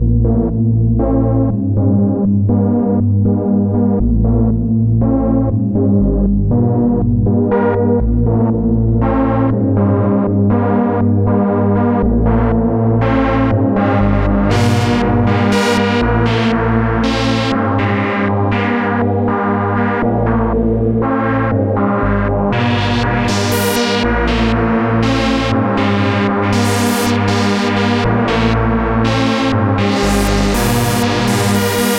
tb EX800Riff01Example.mp3